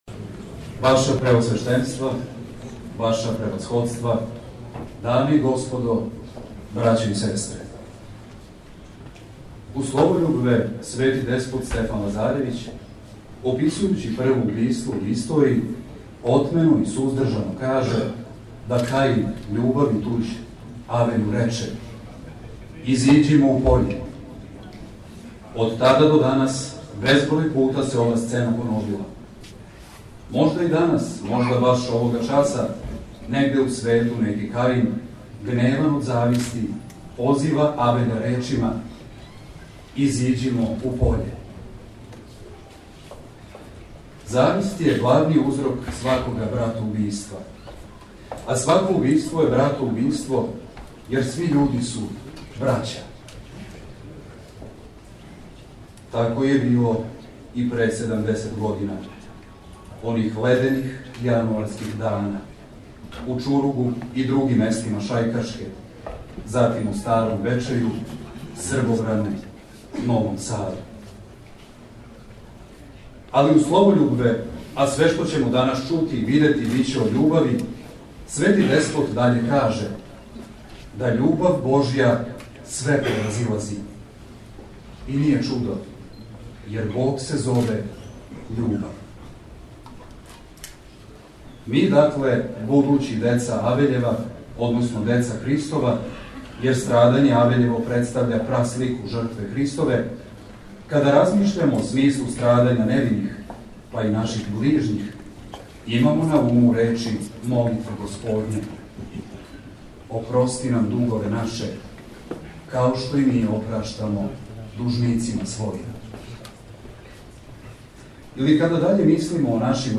У Спомен вртићу Основне школе Ђура Јакшић одржана је Академија Улазите у рај децо бесмртности, у склопу које је приказан филм о рацији, дело Продукције верског програма Епархије бачке.
Звучни запис академије са обраћањем представника покрајинске и општинске власти: